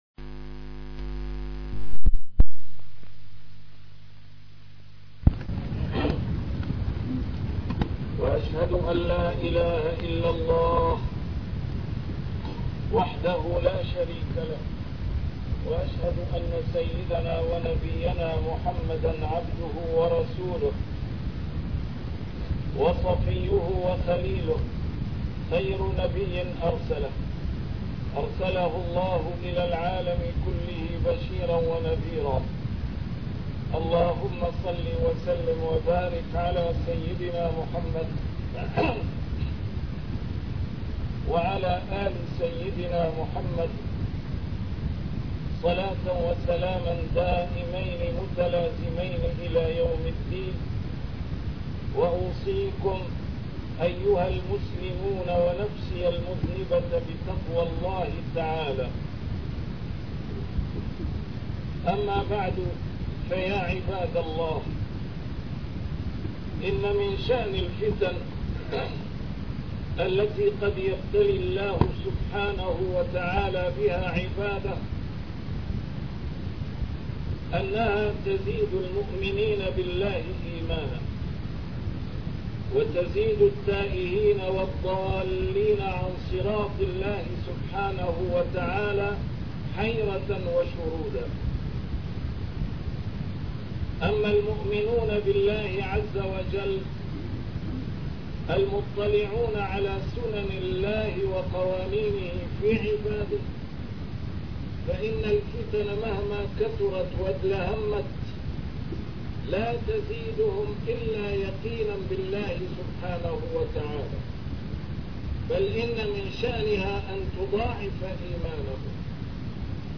A MARTYR SCHOLAR: IMAM MUHAMMAD SAEED RAMADAN AL-BOUTI - الخطب - لماذا لا ينصر الله عباده المؤمنين؟